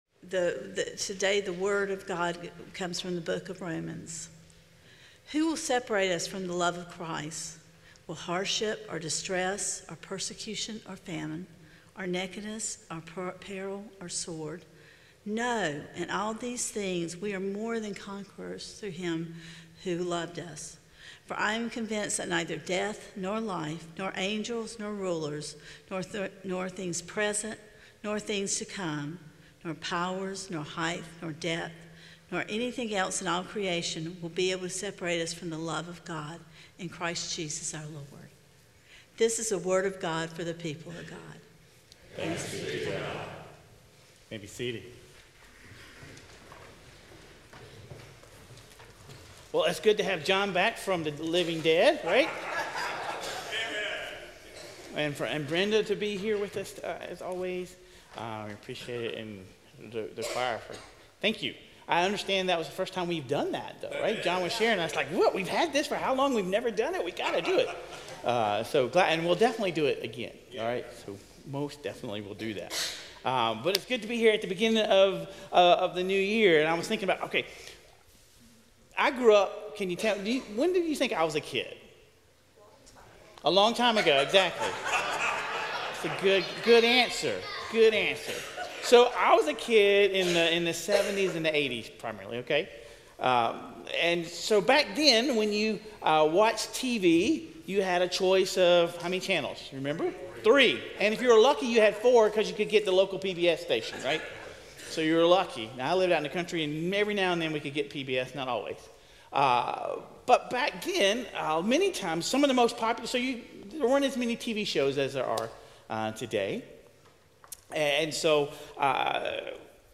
Following the scripture reading, we join the disciples on the Road to Emmaus — men who were heartbroken because the "redeemer" they hoped for had been crucified.
This is a sermon for anyone who has ever felt abandoned by their faith or confused by God's silence.